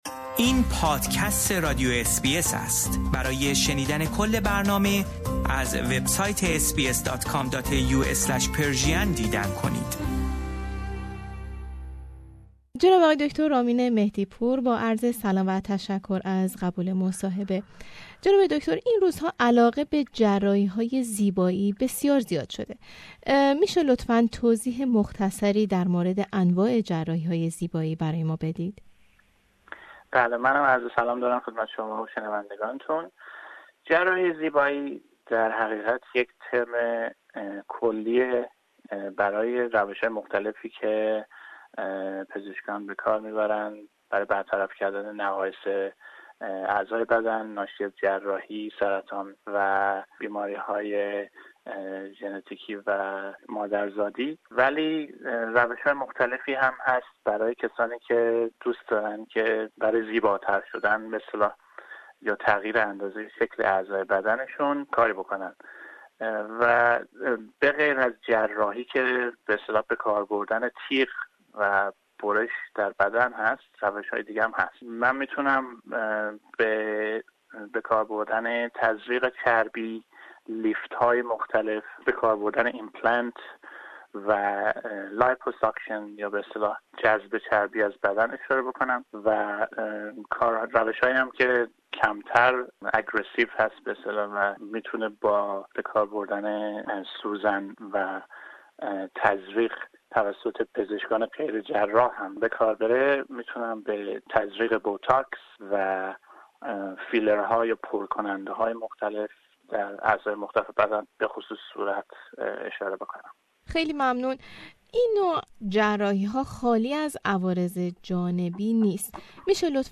به مصاحبه ای